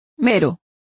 Complete with pronunciation of the translation of mere.